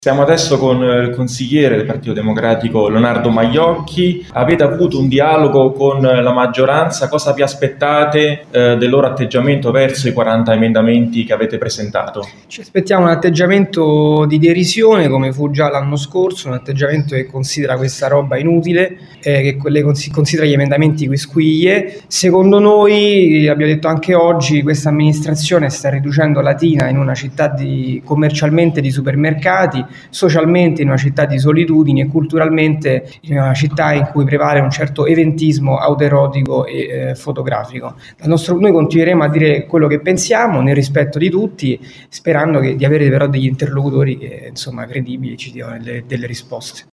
LATINA – Nella giornata di ieri si è tenuta la conferenza stampa di presentazione degli emendamenti che, in sede di bilancio previsionale, saranno presentati dal Partito Democratico di Latina.
Leonardo Majocchi si è pero espresso negativamente sul dialogo che, in sede del consiglio sul bilancio di previsione che si terrà la prossima settimana, si avrà con la maggioranza e l’amministrazione: